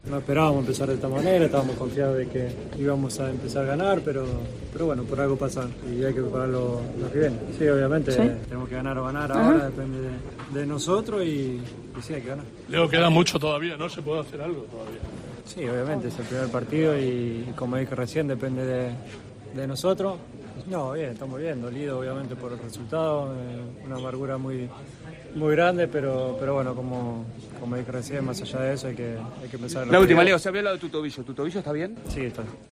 El capitán de Argentina habló tras caer ante Arabia Saudí en el estreno de los argentinos en el Mundial de Qatar.